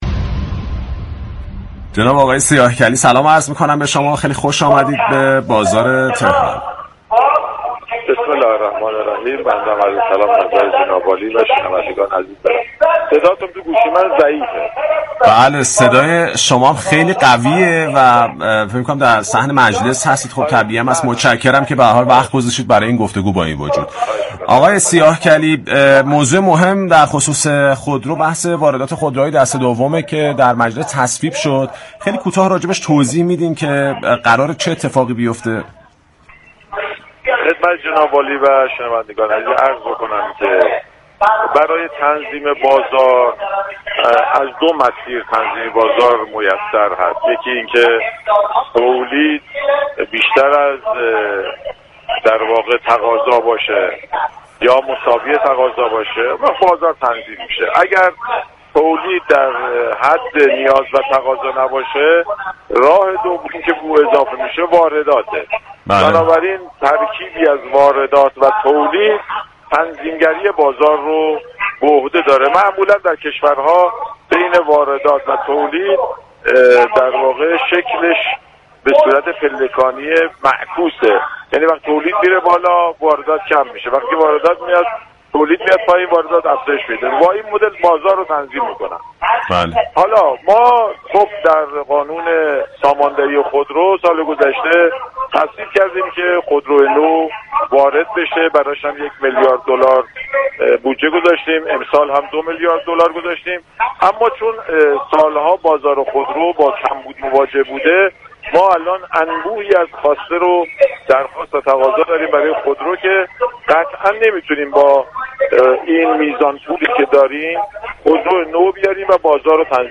به گزارش پایگاه اطلاع رسانی رادیو تهران، لطف الله سیاهكلی عضو كمیسیون صنایع و معادن مجلس شورای اسلامی در گفت و گو با «بازار تهران» درخصوص تصویب قانون وارات خودروهای كاركرده، اظهار داشت: در بازار خودرو عرضه و تقاضا حرف اول را می‌زند.